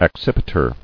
[ac·cip·i·ter]